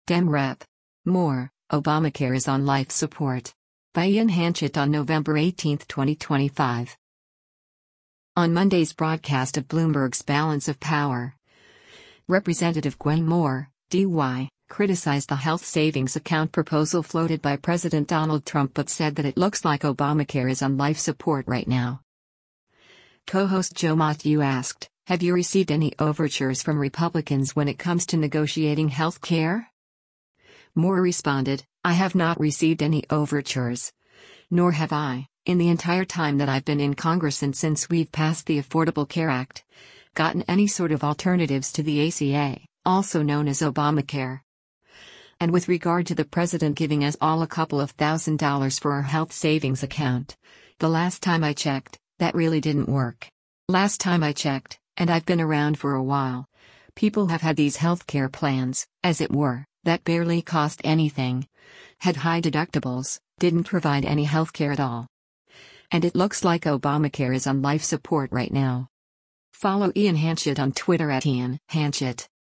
On Monday’s broadcast of Bloomberg’s “Balance of Power,” Rep. Gwen Moore (D-WI) criticized the health savings account proposal floated by President Donald Trump but said that “it looks like Obamacare is on life support right now.”